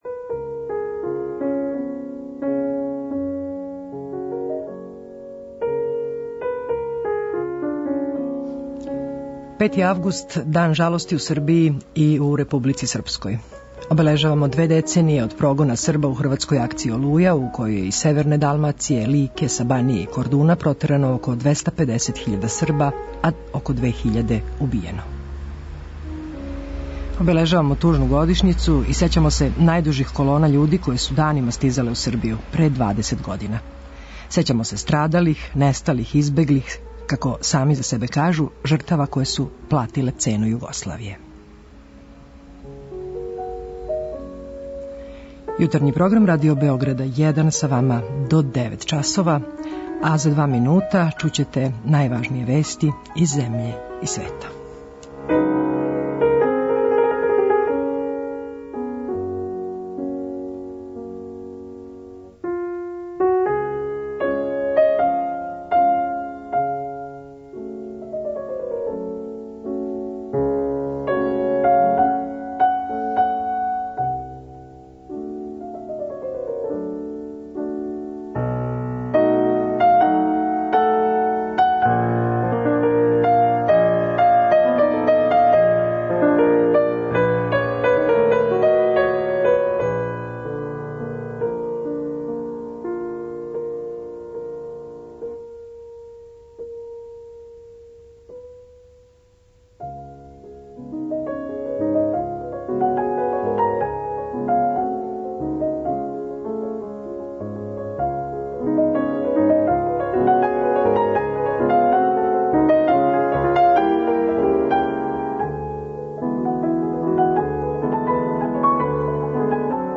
У оквиру емисије емитујемо: 06:03 Јутарњи дневник; 07:00 Вести; 08:00 Вести
У Јутарњем програму чућете реч представника српских удружења у Хрватској и сећања прогнаних, а говорићемо и о догађајима који се овим поводом организују у Србији и Хрватској.